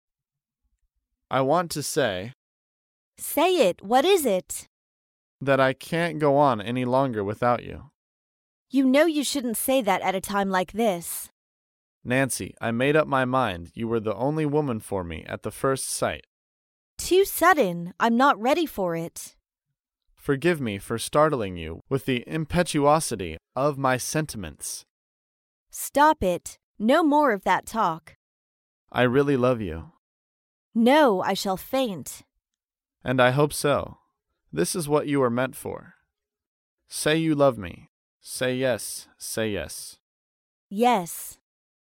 在线英语听力室高频英语口语对话 第166期:表达爱意的听力文件下载,《高频英语口语对话》栏目包含了日常生活中经常使用的英语情景对话，是学习英语口语，能够帮助英语爱好者在听英语对话的过程中，积累英语口语习语知识，提高英语听说水平，并通过栏目中的中英文字幕和音频MP3文件，提高英语语感。